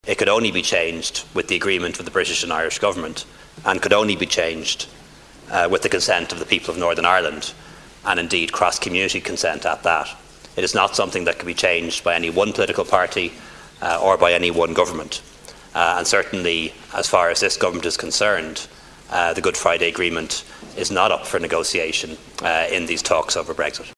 Taoiseach Leo Varadkar says it’s something the government won’t consider…………